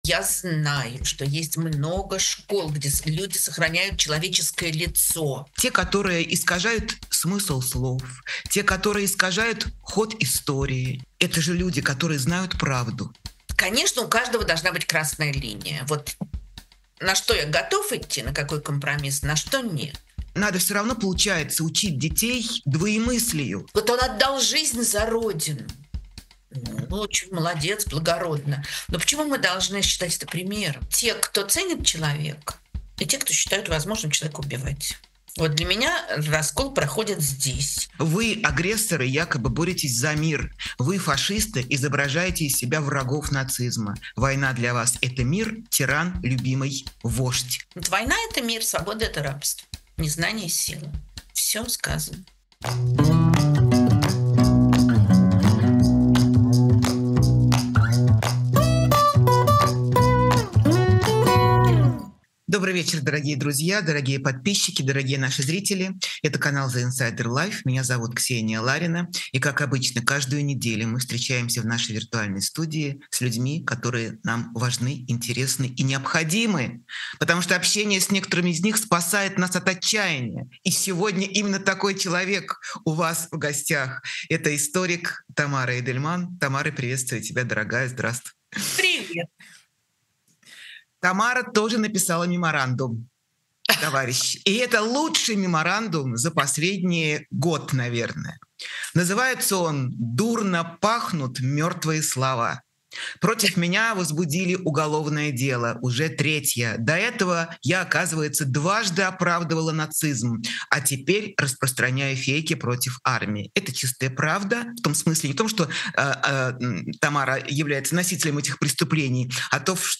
Эфир ведёт Ксения Ларина
Гость — историк Тамара Эйдельман.